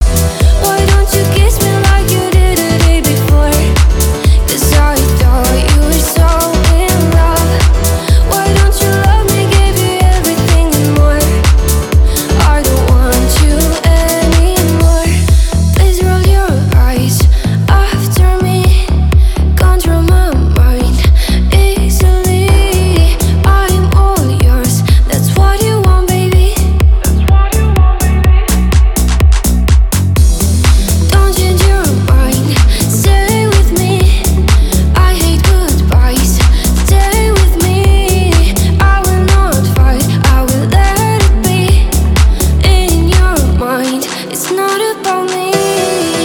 • Качество: 320, Stereo
Dance Pop
красивый женский голос